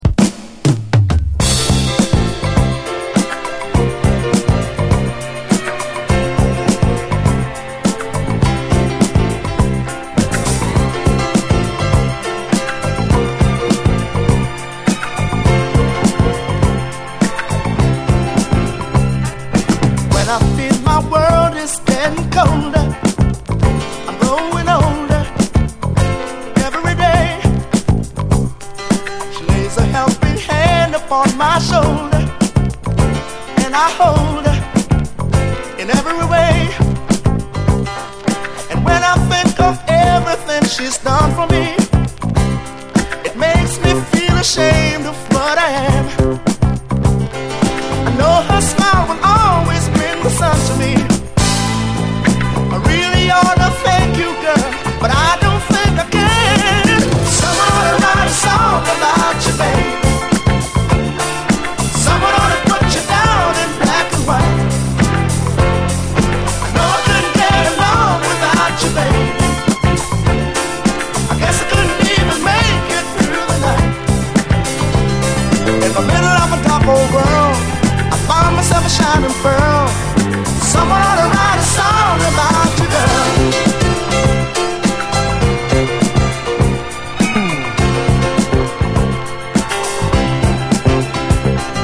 サックスやフルート、オーボエなど複数の管楽器を一度に口にくわえて同時に演奏してしまう
ソウルもジャズも黒人霊歌も垣根なく飲み込んだ間口の広いユニティー感、溢れるヒューマニティーこそが魅力！
いわゆるソウル・ジャズ、というカテゴリーには到底収まりきらない傑作です！